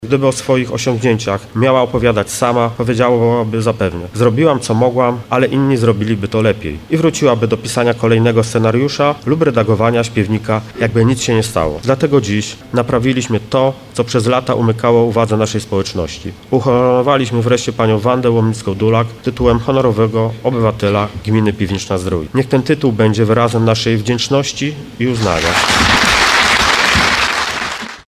Tytuł wręczono jej w piątek (25.07) podczas uroczystej sesji rady miejskiej.
mówił Tomasz Michałowski, burmistrz Piwnicznej-Zdroju.